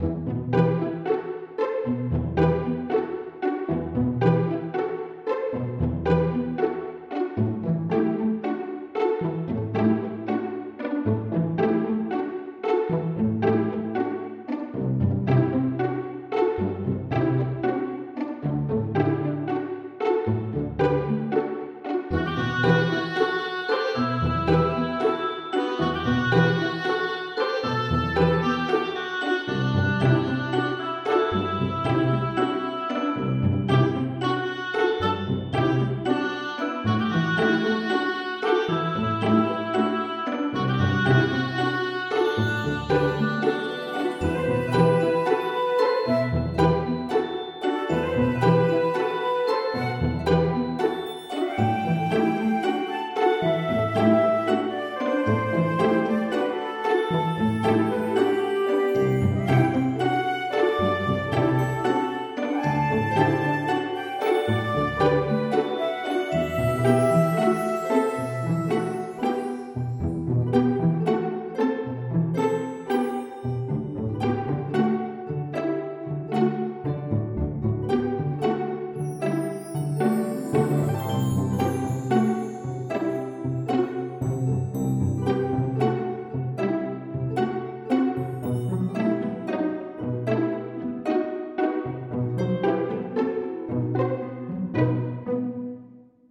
- haunting melodic loop